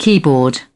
keyboard.mp3